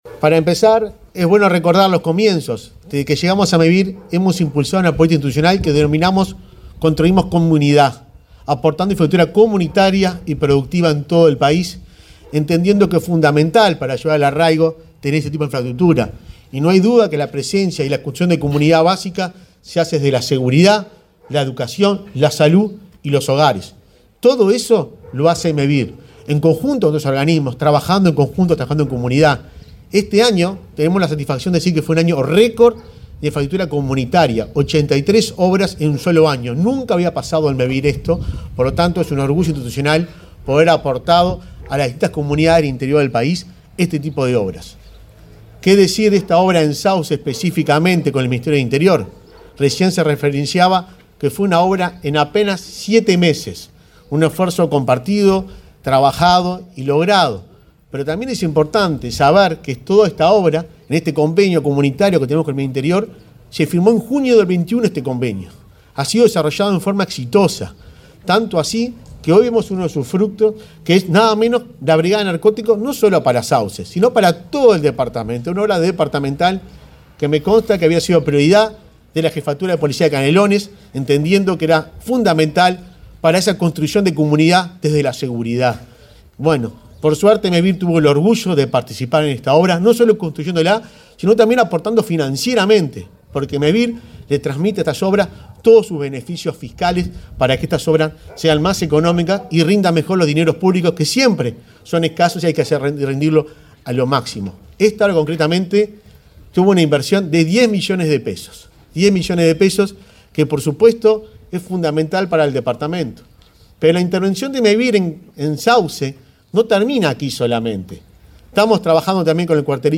Palabras de autoridades en acto de inauguración de Brigada Departamental Antidrogas
Palabras de autoridades en acto de inauguración de Brigada Departamental Antidrogas 20/12/2023 Compartir Facebook X Copiar enlace WhatsApp LinkedIn Este miércoles 20, el presidente de Mevir, Juan Pablo Delgado, y el jefe de Policía de Canelones, Víctor Trezza, se expresaron en el acto de inauguración de la Brigada Departamental Antidrogas de la localidad de Sauce, departamento de Canelones.